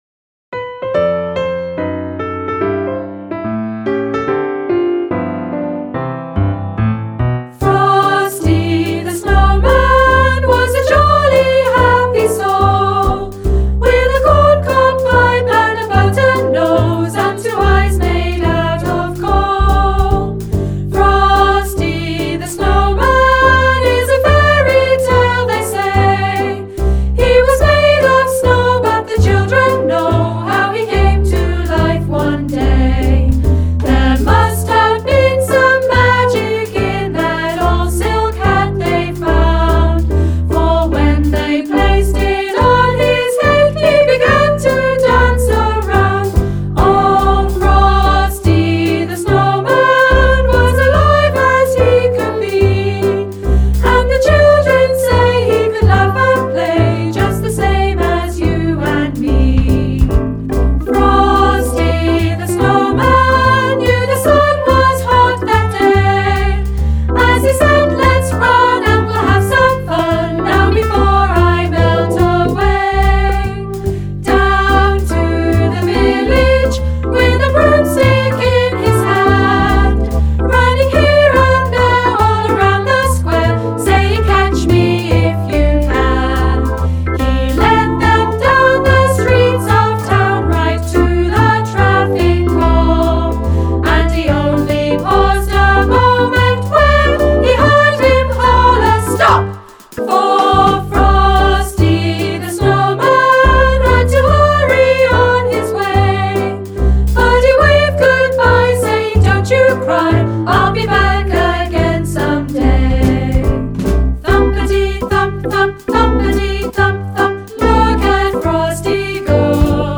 A Performance Track plays the entire song with vocals.